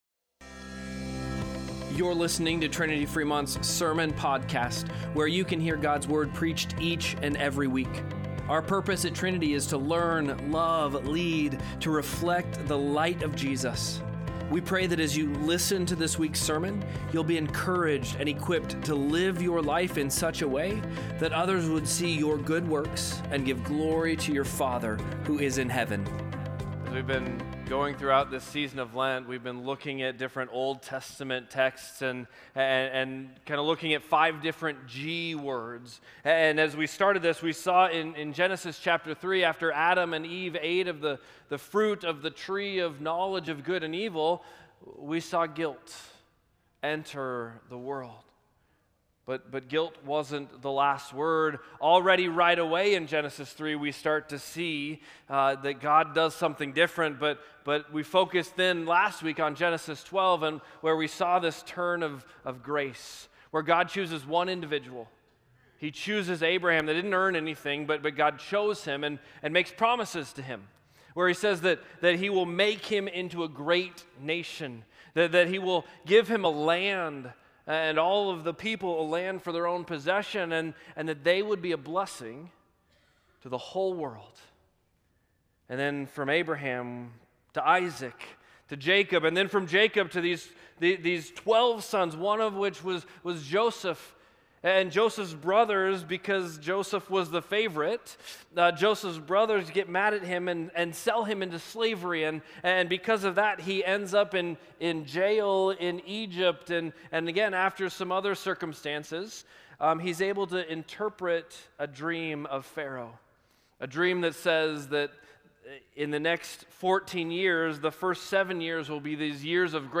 Sermon-Podcast-03-08.mp3